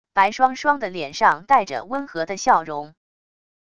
白双双的脸上带着温和的笑容wav音频生成系统WAV Audio Player